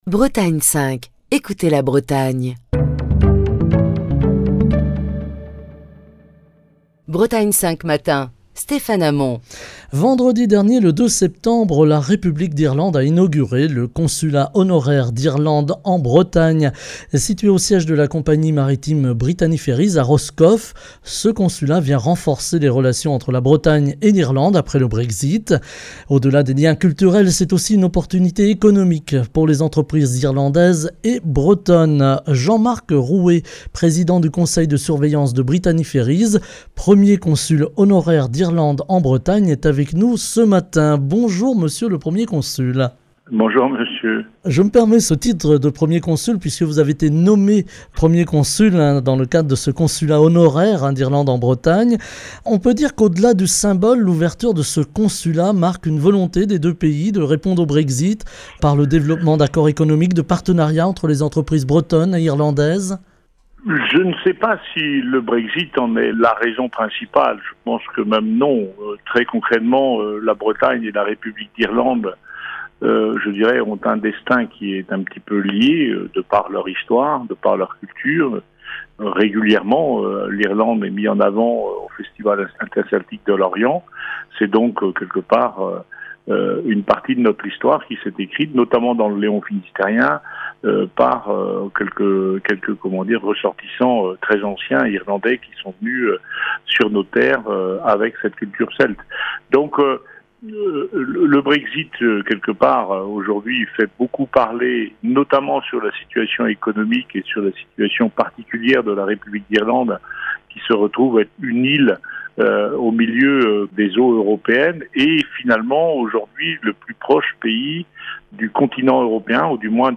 Invité : Jean-Marc Roué, président du conseil de surveillance de Brittany Ferries, premier consul honoraire d’Irlande en Bretagne.